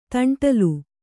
♪ taṇṭalu